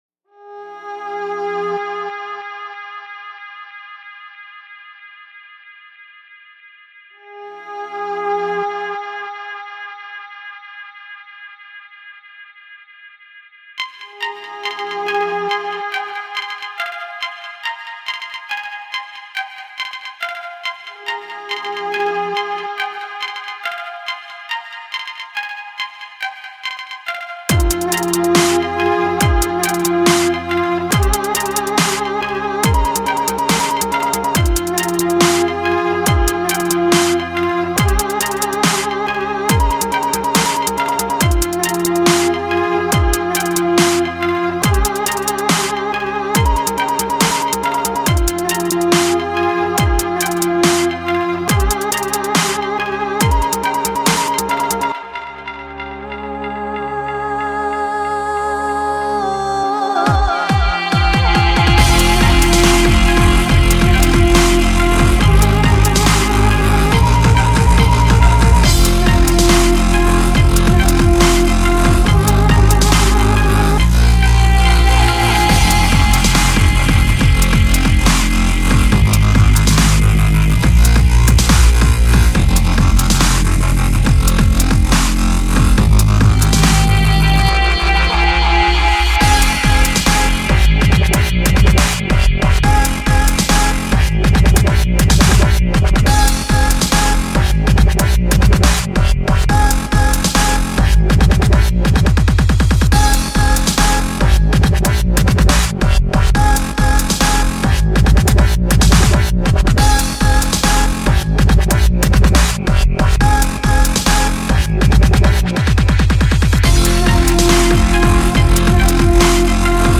Категория: Trance